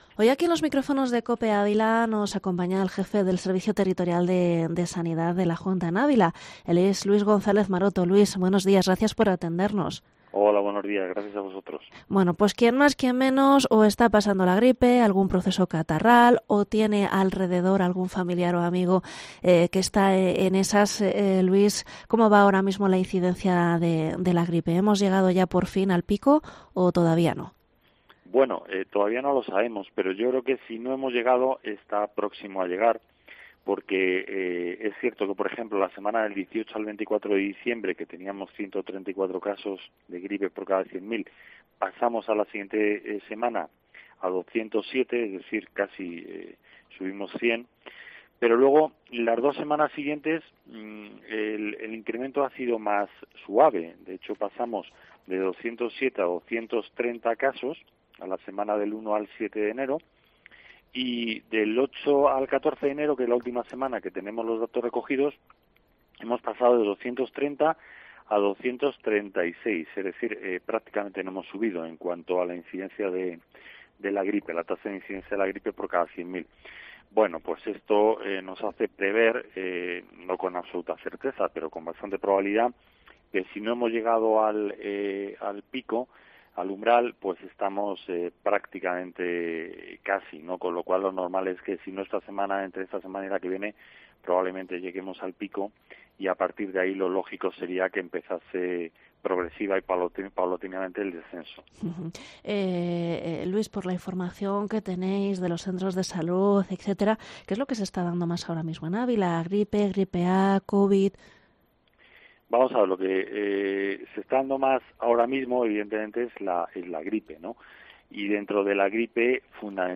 ENTREVISTA al jefe del Servicio Territorial de Sanidad, Luis González Maroto